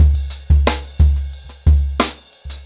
InDaHouse-90BPM.5.wav